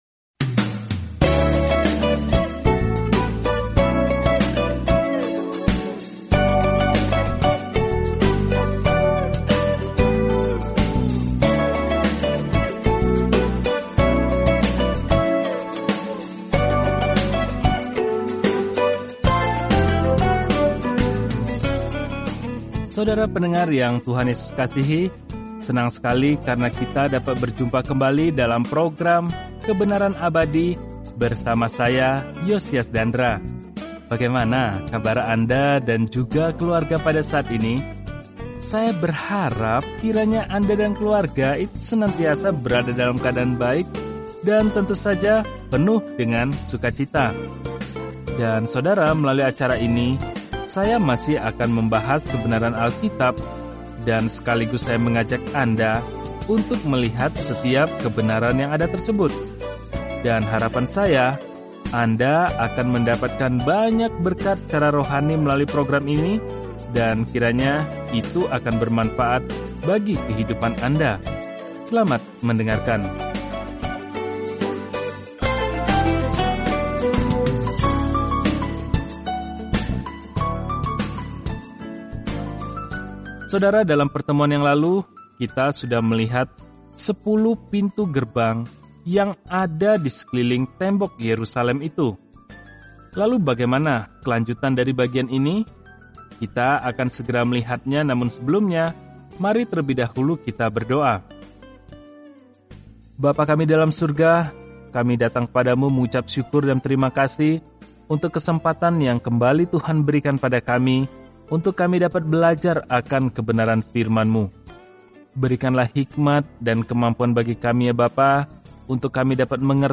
Firman Tuhan, Alkitab Nehemia 4 Hari 5 Mulai Rencana ini Hari 7 Tentang Rencana ini Ketika Israel kembali ke tanah mereka, kondisi Yerusalem buruk; seorang manusia biasa, Nehemia, membangun kembali tembok di sekeliling kota dalam buku Sejarah terakhir ini. Telusuri Nehemia setiap hari sambil mendengarkan pelajaran audio dan membaca ayat-ayat tertentu dari firman Tuhan.